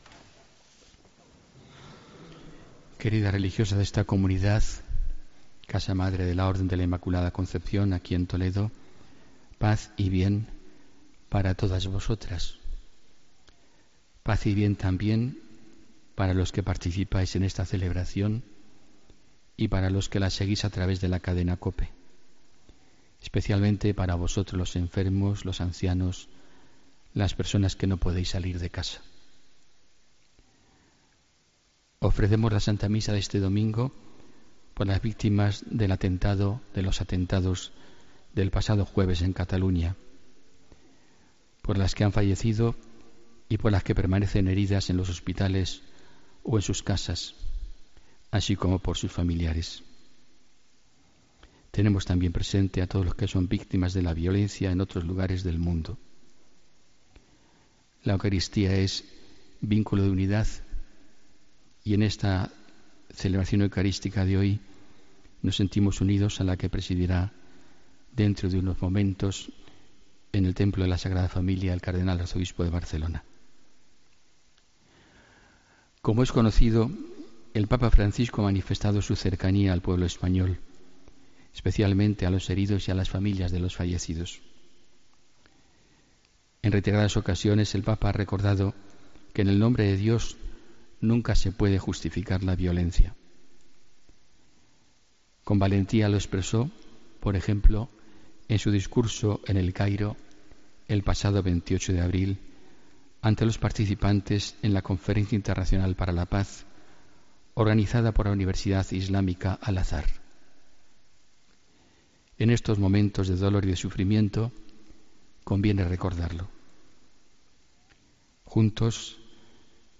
Escucha la Homilía del 20 de agosto de 2017